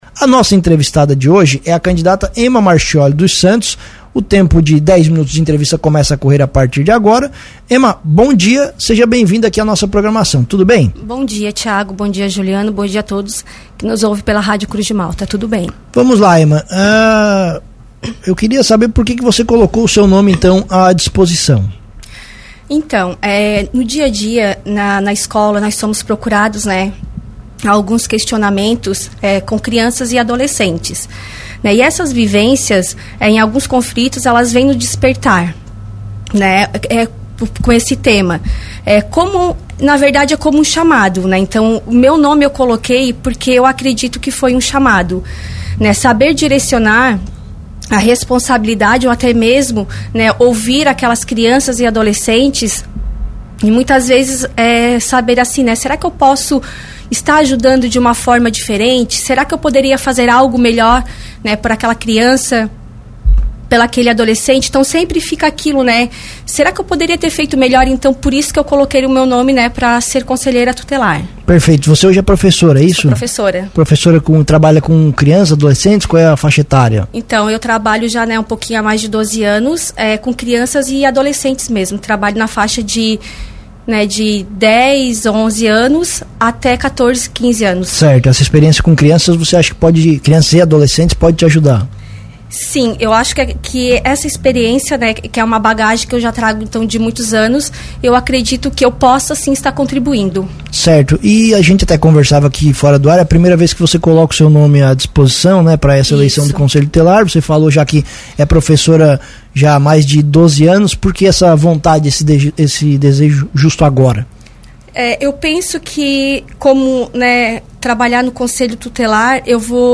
As entrevistas vão ao ar todos os dias a partir das 8h, com tempo máximo de 10 minutos cada.